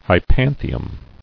[hy·pan·thi·um]